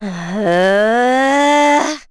Laudia-Vox_Casting3.wav